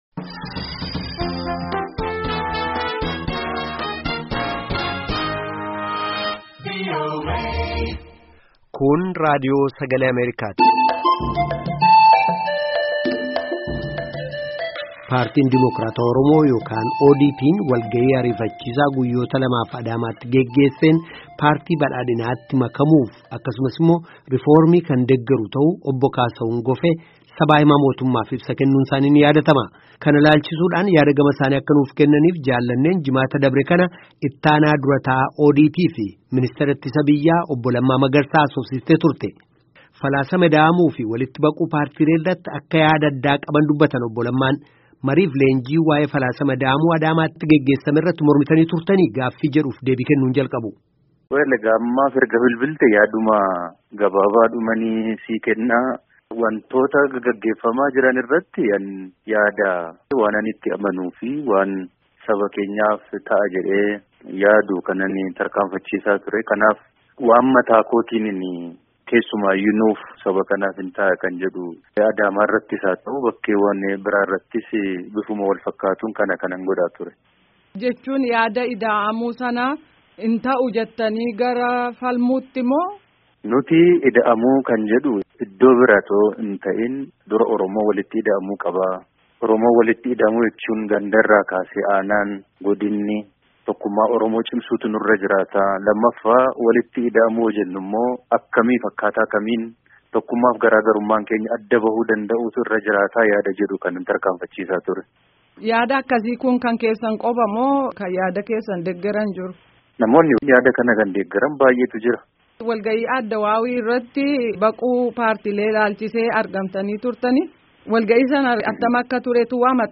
Gaaffii fi Deebii Guutuu Obbo Lammaa Magarsaa Waliin Geggeessamee fi Deebii Paartii Badhaadhimaa